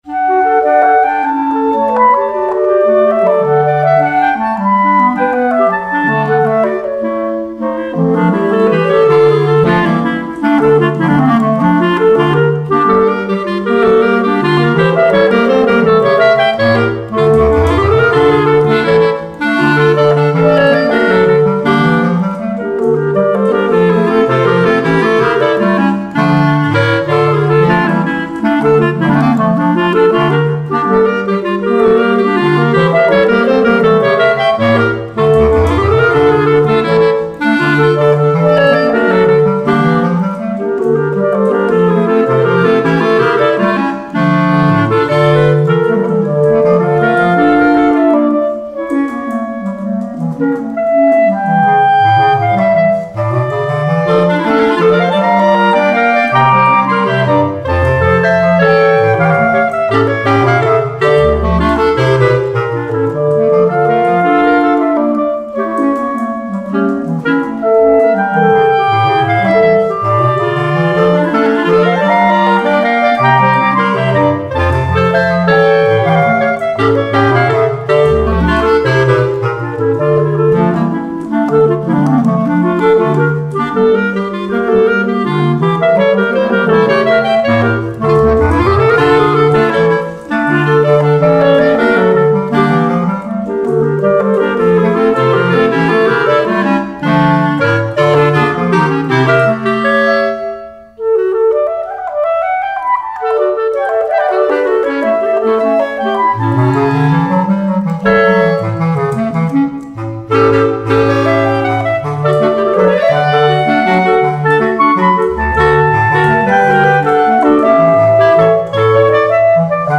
Bambuco